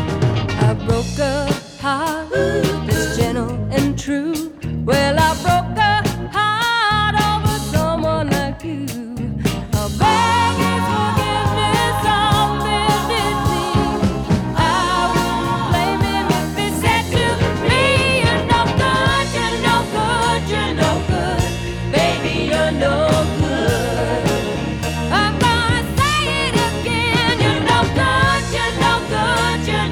• Rock